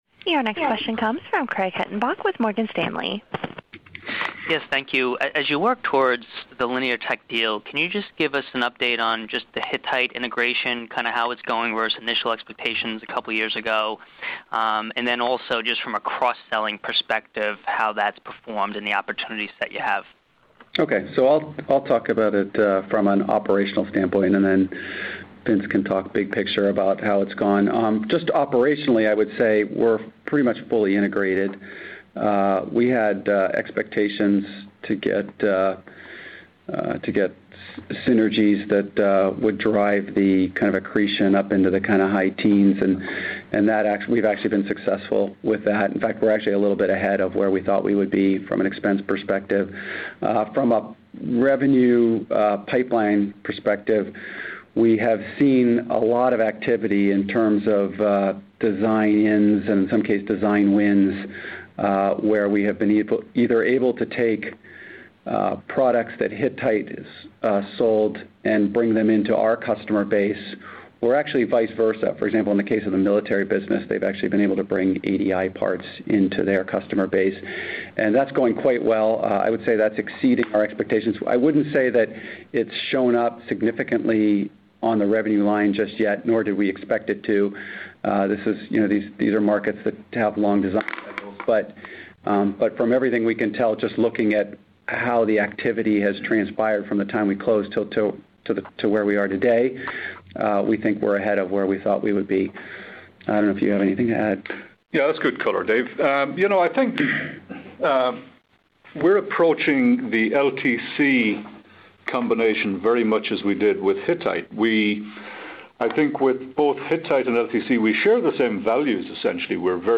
During ADI's Q3 FY16 earnings call